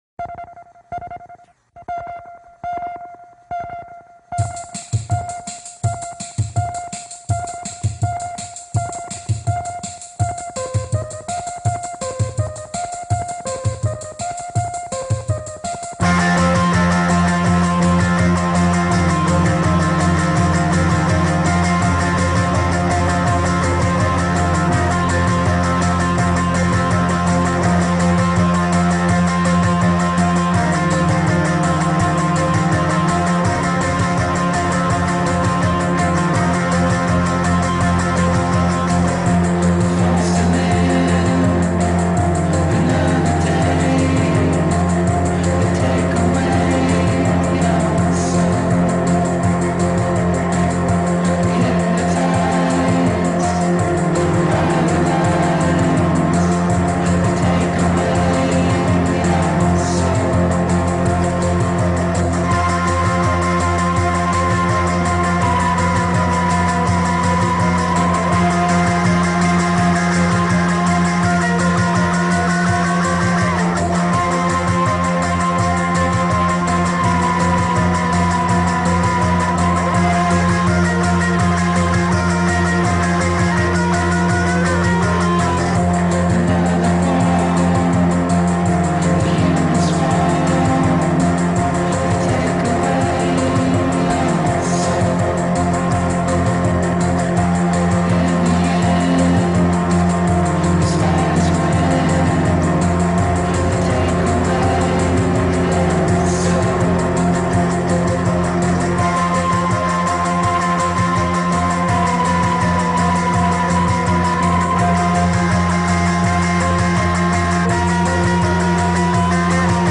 garage rock
infectiously dirty power pop sensibility